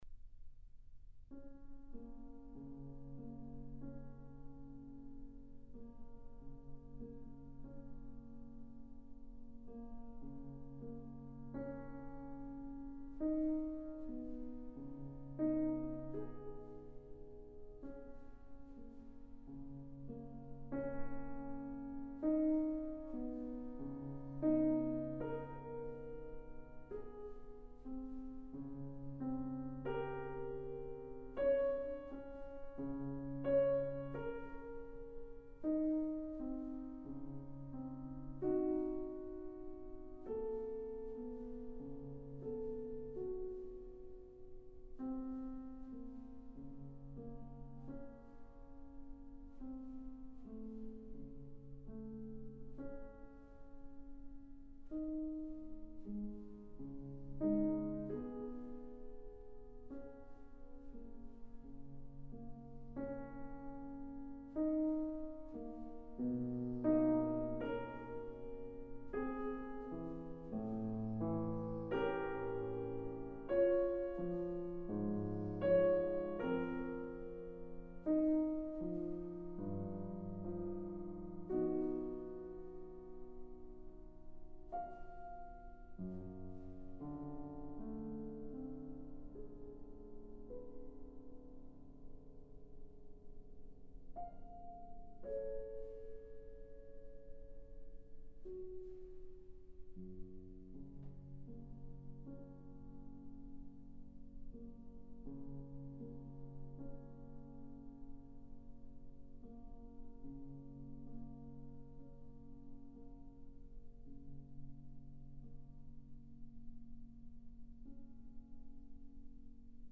very slow and solemn movement for piano alone
piano